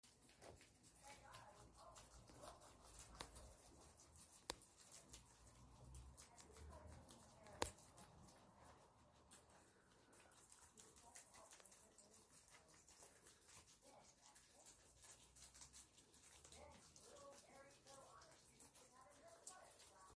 love hearing the crunching sounds